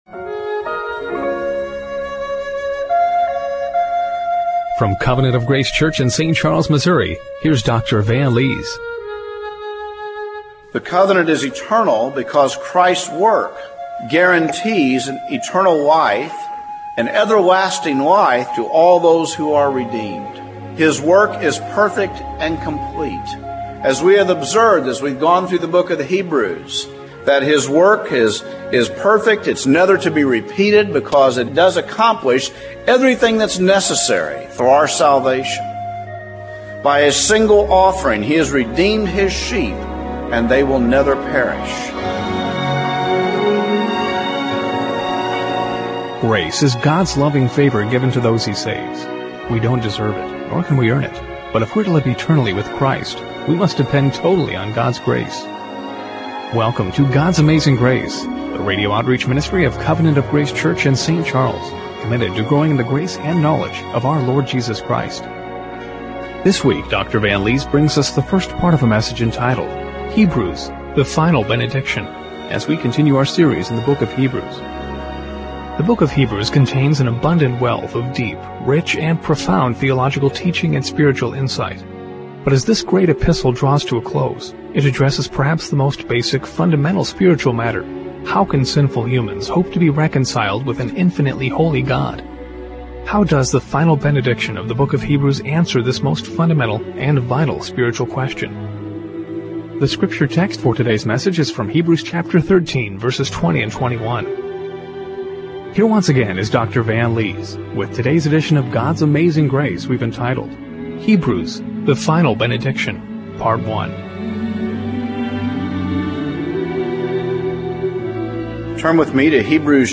Hebrews 13:20-21 Service Type: Radio Broadcast How can sinful humans hope to be reconciled with an infinitely Holy God?